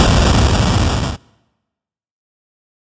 SunkSFX.ogg